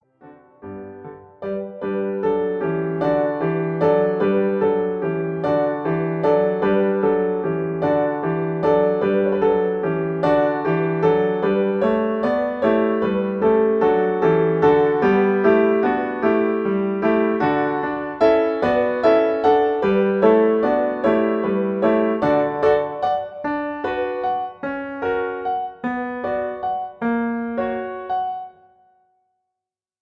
MP3 piano acompaniment
in G Major